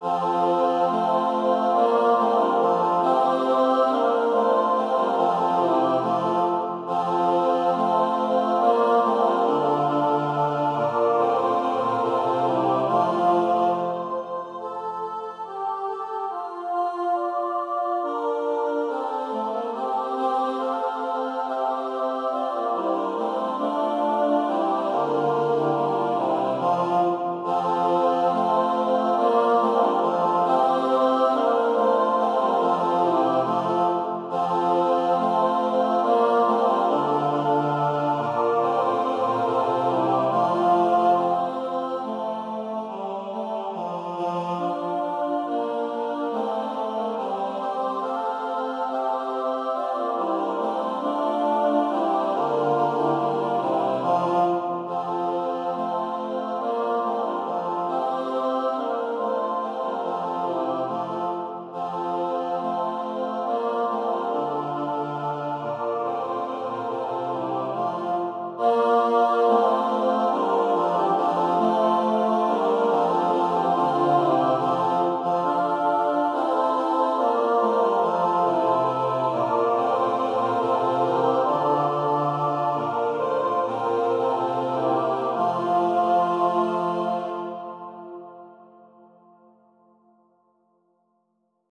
Number of voices: 4vv Voicing: SATB Genre: Sacred, Anthem
Language: English Instruments: A cappella